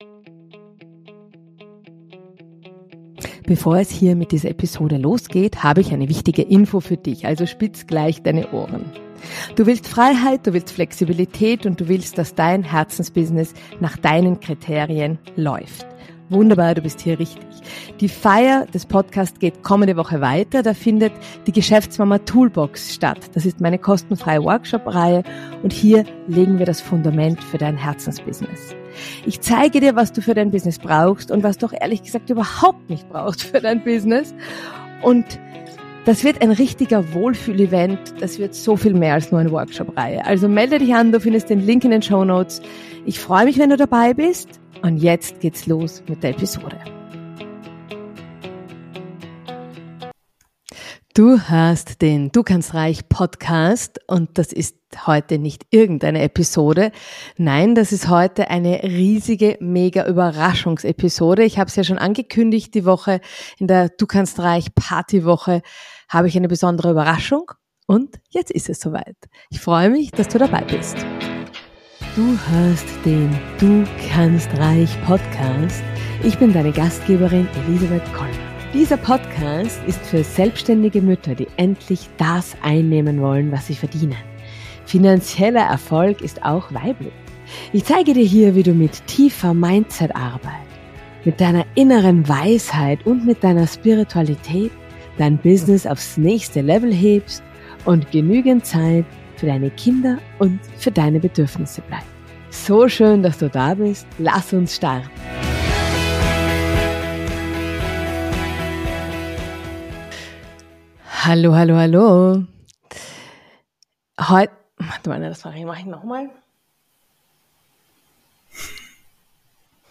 #03 Special Edition Expertinnen Talk: Homeoffice mit Kids ~ Du kannst reich Podcast